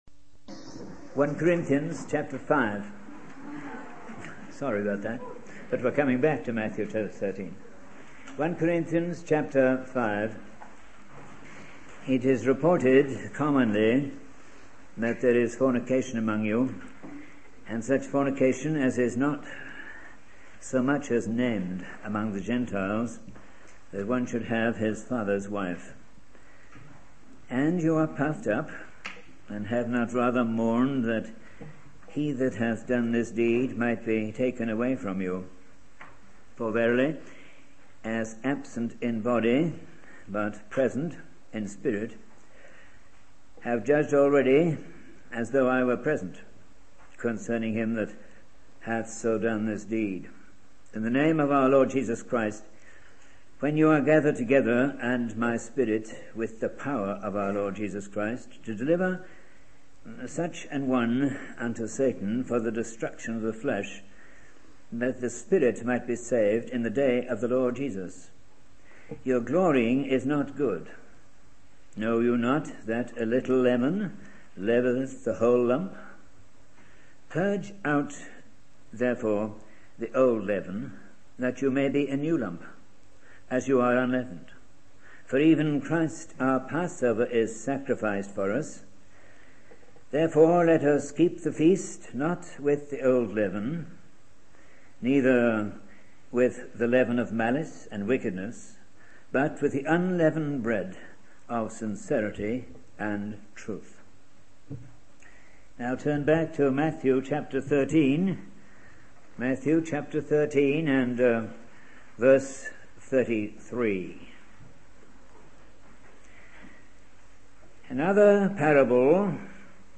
In this sermon, the preacher discusses the parable of the three measures of meal. He explains that the parable represents fellowship with God in service, which can be spoiled by the intrusion of corruption.